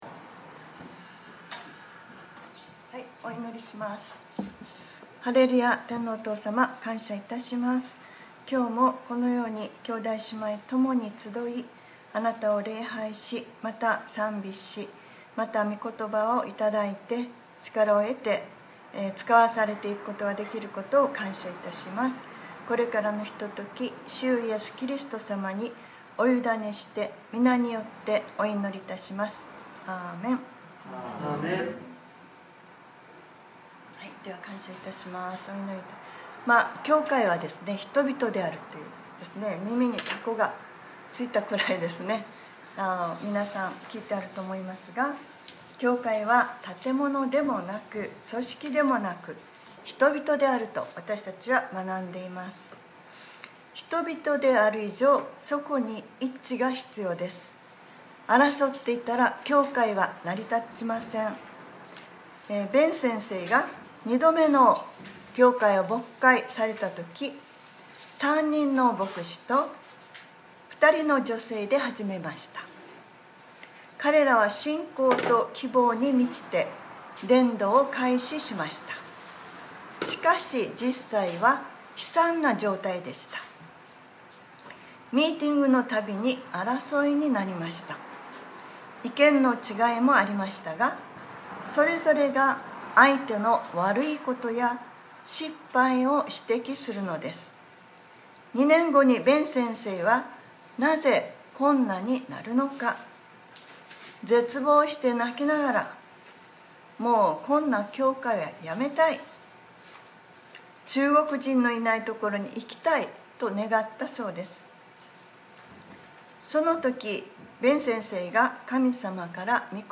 2021年09月19日（日）礼拝説教『 肯定的思考 』